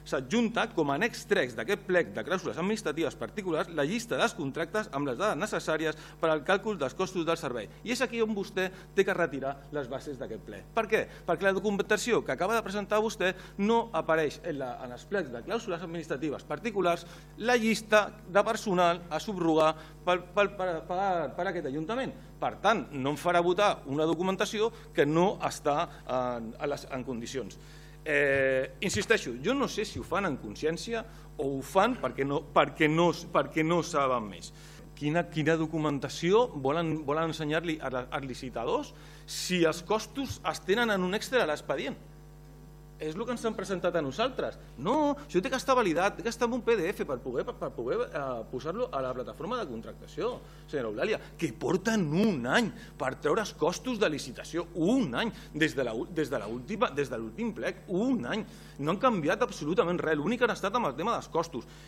Tant el regidor no adscrit, Albert Sales, com el portaveu d’ERC, Alex Van Boven, van criticar que els costos laborals no estiguessin inclosos en els plecs administratius, tot i aparèixer en l’expedient: